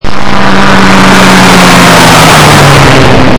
Play, download and share VROOOOM original sound button!!!!
bmwdriveby-1.mp3